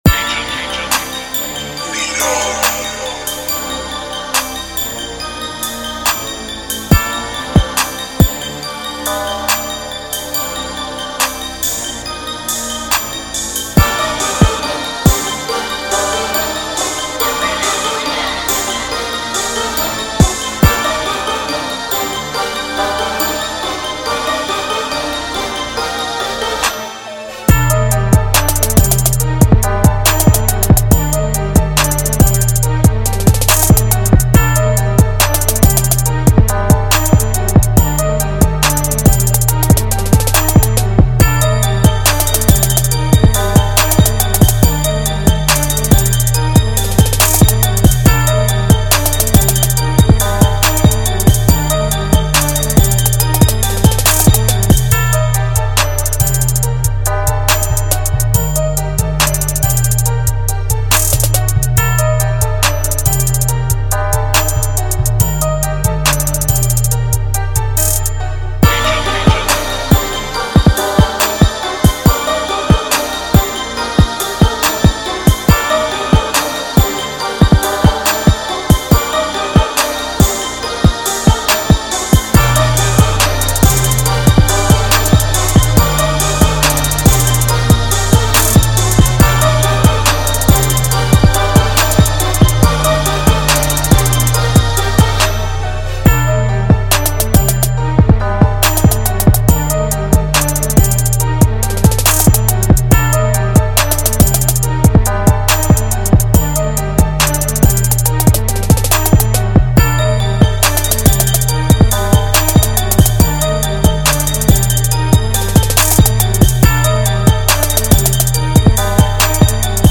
Drill Instrumental